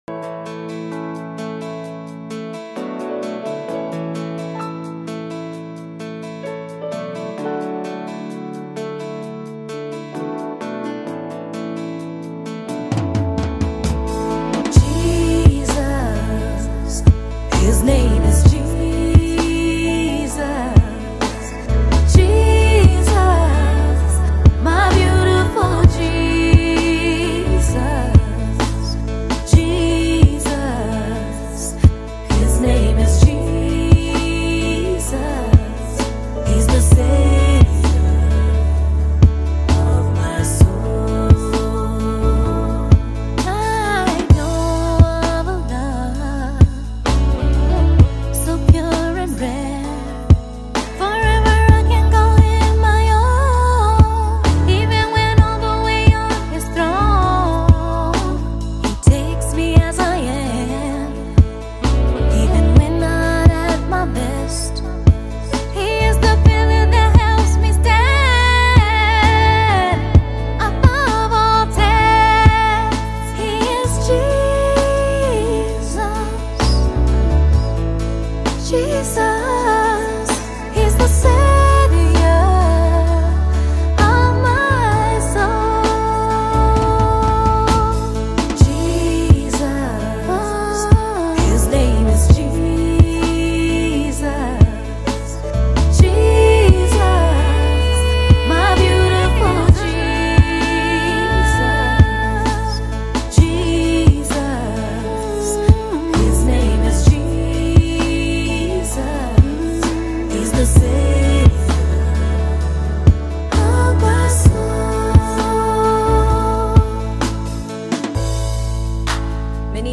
NEW GOSPEL AUDIO